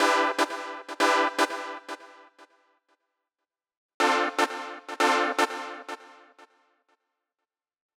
29 Synth PT2.wav